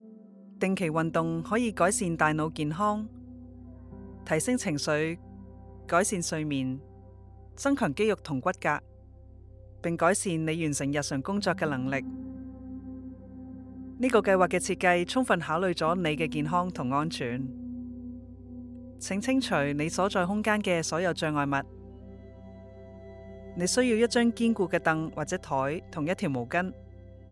E-learning
Her warm female tone is great for both professional narrations and positive representation for your brand!